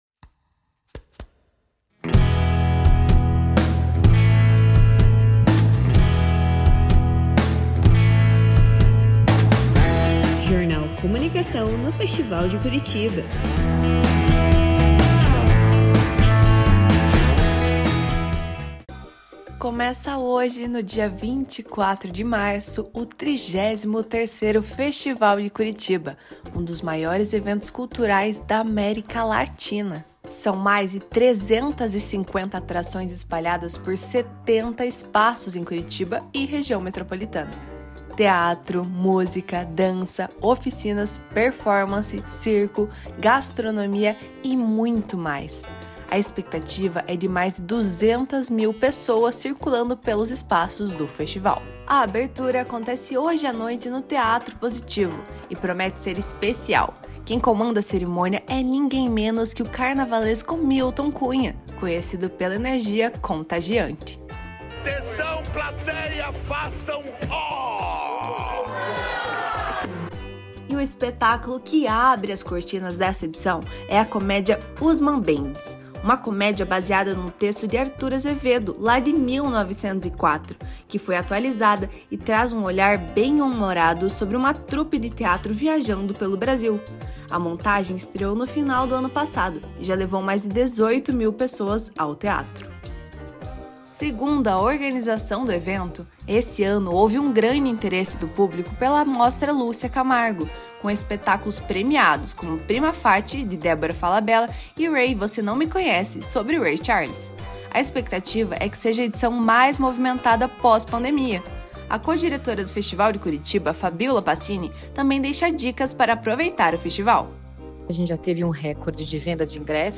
Tempo de reportagem: 4’31’’